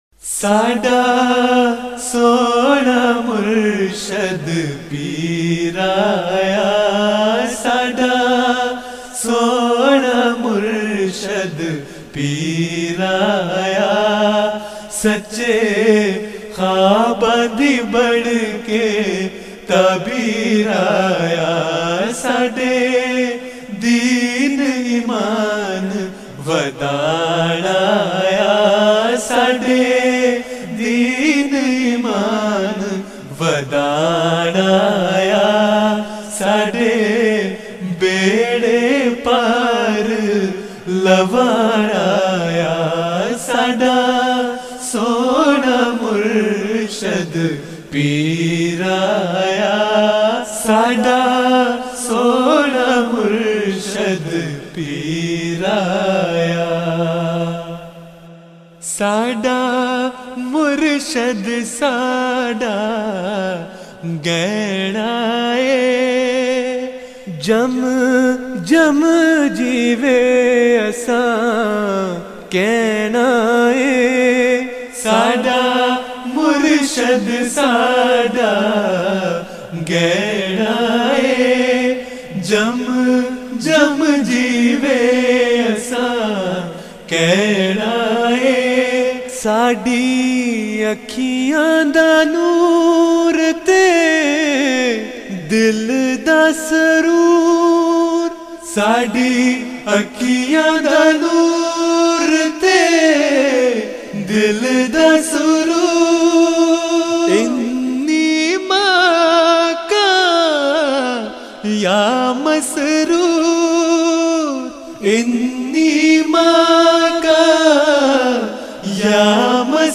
Voice: Group
Jalsa Salana Germany 2012